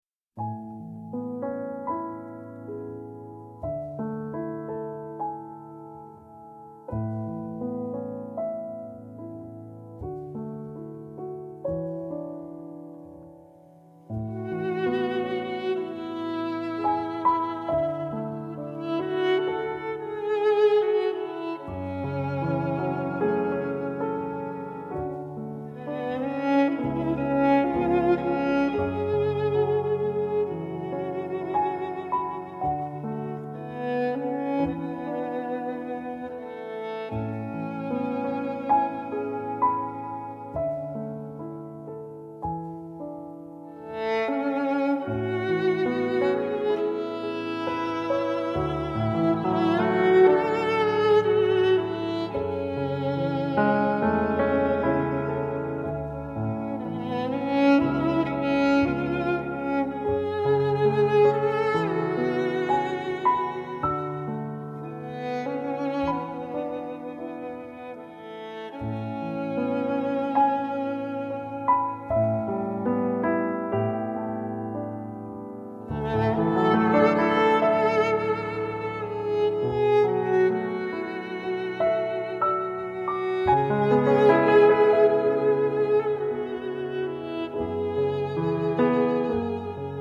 ★ 弦樂滑潤凝脂、沁人心脾，柔媚剔透一如頂級絲綢般的高貴觸感。
它並不把所有樂器的聲響壓得飽飽的，而呈現了相當寬廣的空間感，您可以感受到音樂在其中流動著
DSD 錄音技術保留了樂器最全面的音質韻味
十二首您熟悉的電影金曲，以鋼琴、豎琴、吉他和提琴浪漫完美的呈現，首首百聽不厭。